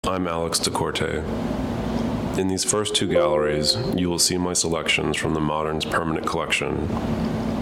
Clicking on it will let you listen to Da Corte himself as he comments on a work or on his own technique.
You might want to first read the introductory plaque, and you can listen to Da Corte's welcome message by clicking on the headphones: